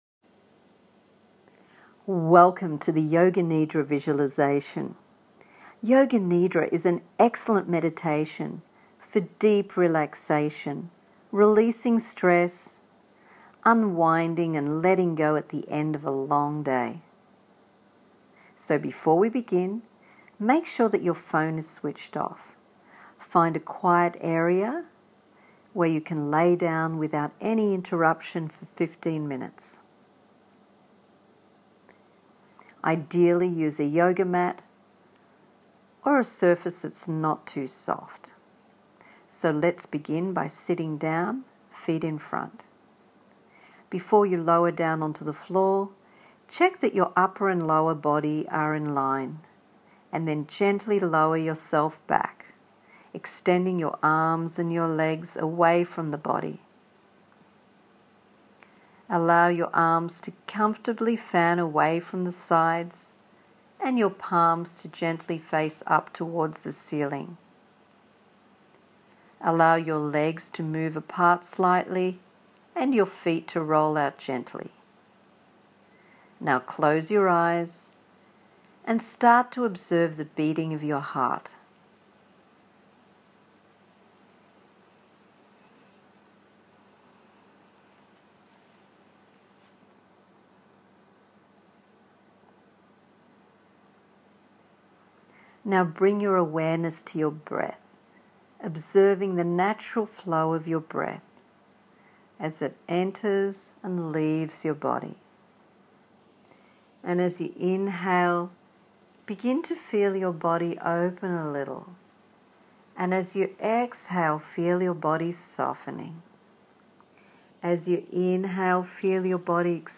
Yoga+nidra.m4a